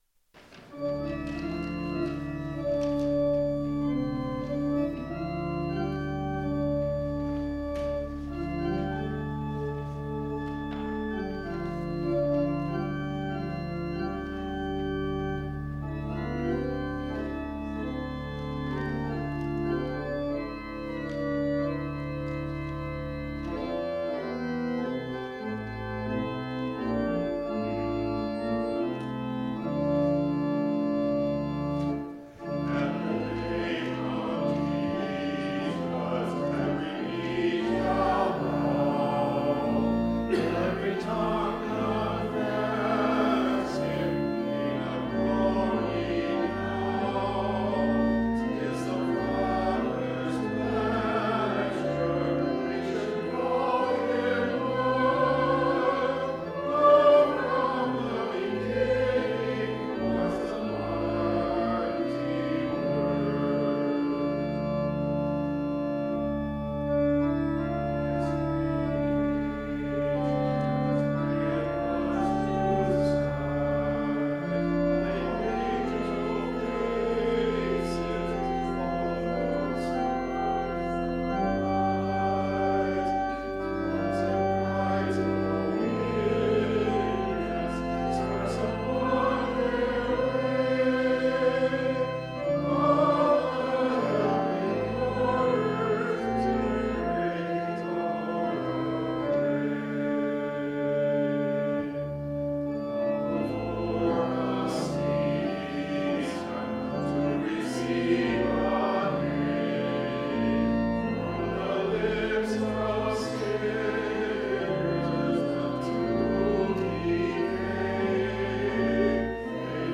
Full Sermon Draft Download Biblical Text: Mathew 25:14-30 I’m endlessly fascinated with the parable of the talents.